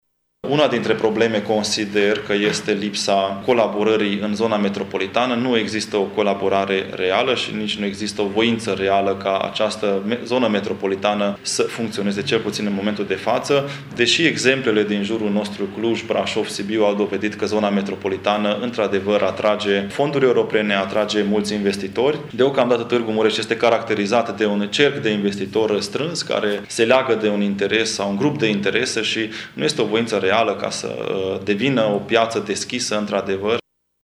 El a prezentat astăzi, intr-o conferinta de presa, o parte din programul cu care doreşte să intre în prealegerile organizate de formaţiunile maghiare pentru desemnarea candidatului la Primăria Târgu-Mureş.